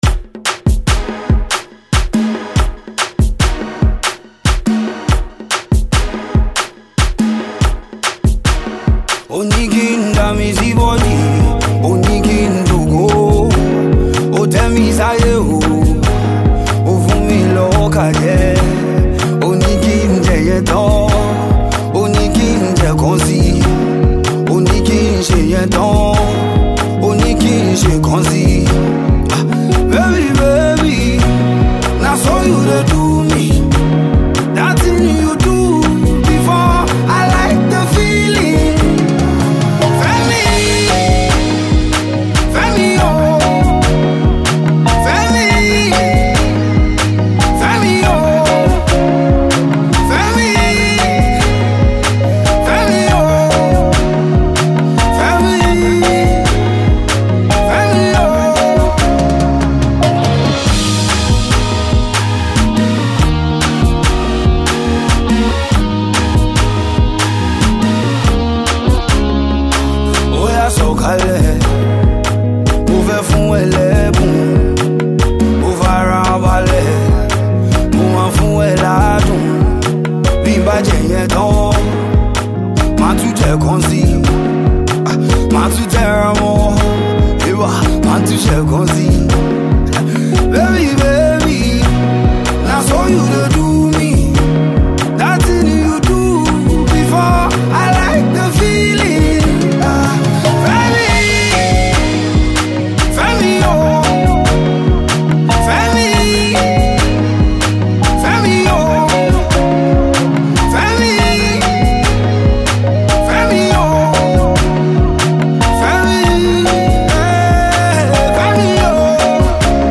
single
It has that afro-erotica essence and it’s a fresh tune.